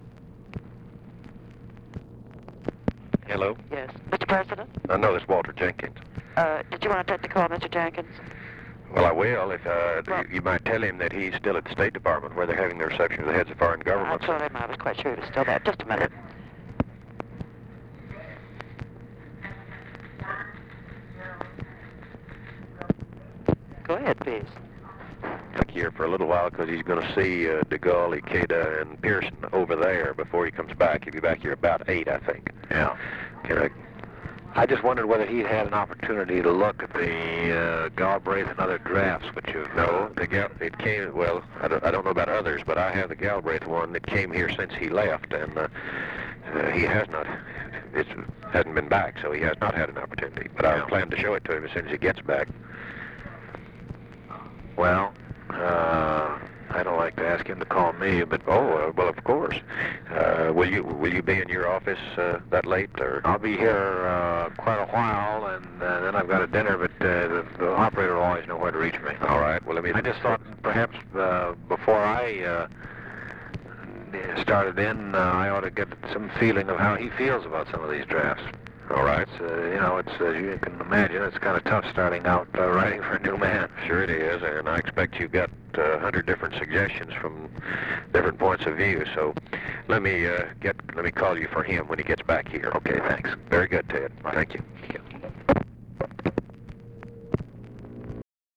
Conversation with WALTER JENKINS, November 25, 1963